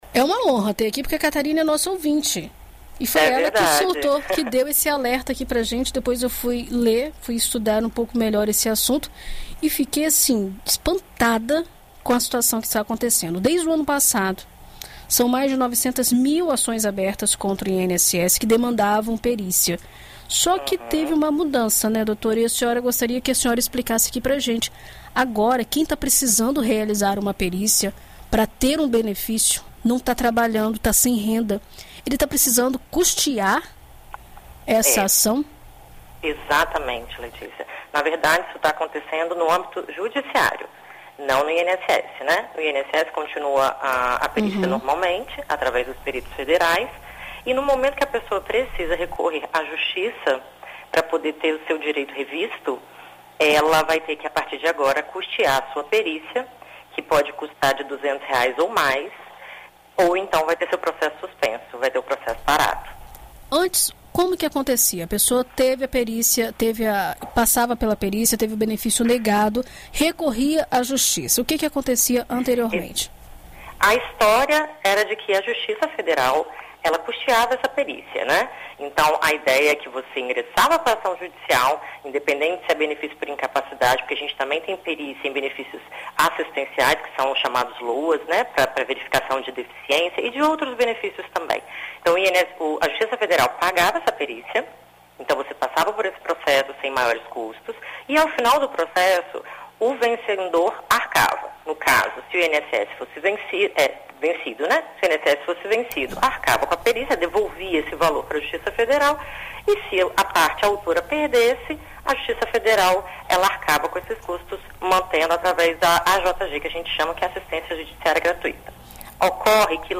Em entrevista à BandNews FM Espírito Santo nesta quinta-feira